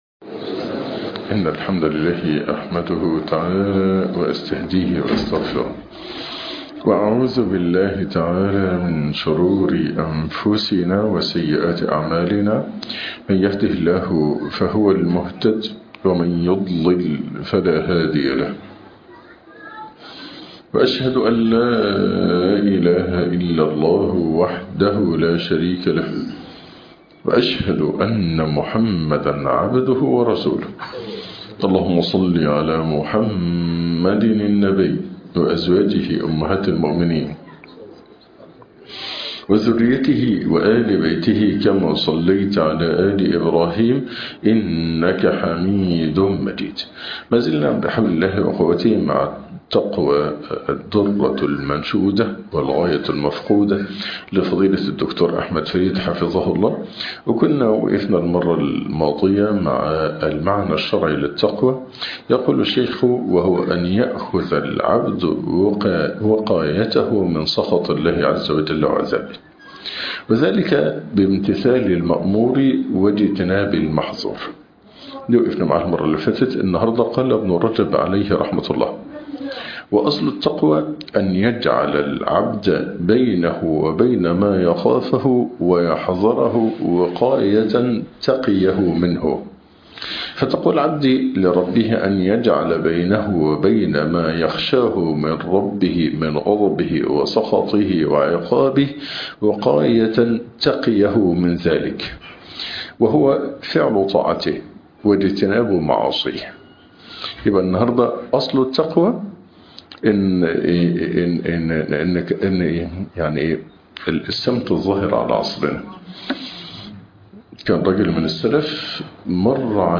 ( شرح كتاب التقوى ) الدرس الثالث